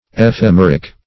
ephemeric - definition of ephemeric - synonyms, pronunciation, spelling from Free Dictionary Search Result for " ephemeric" : The Collaborative International Dictionary of English v.0.48: Ephemeric \E*phem"e*ric\, a. Ephemeral.